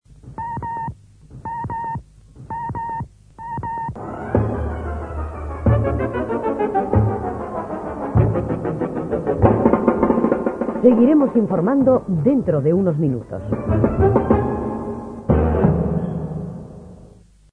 Indicatiu i toc de l'hora, entrada i sortida butlletí de cada 15 minuts.